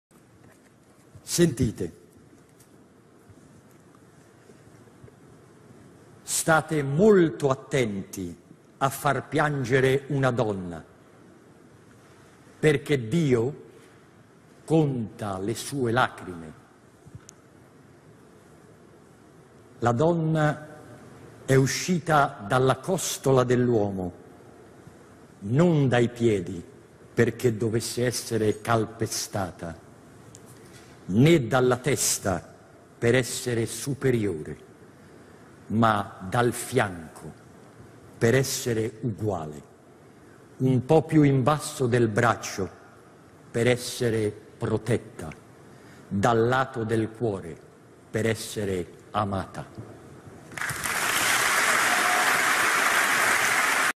benigni_festa_della_donna_discorso.mp3